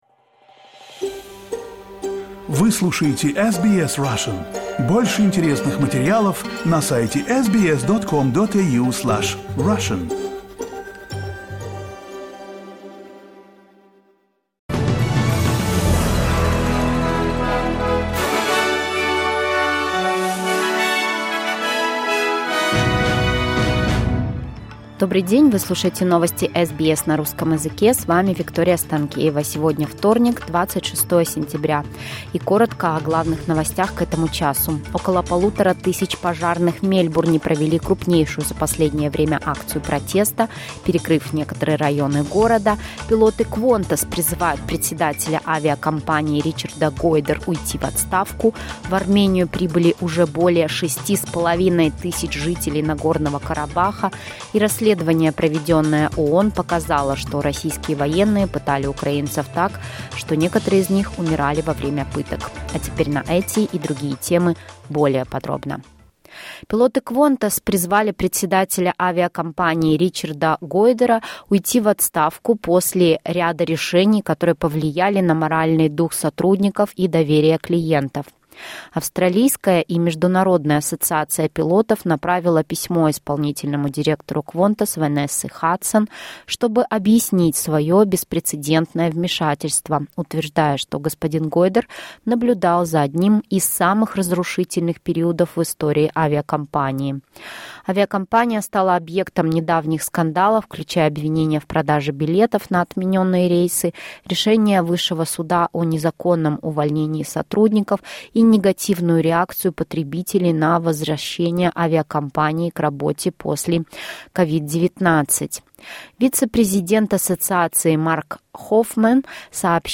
SBS news in Russian — 26.09.2023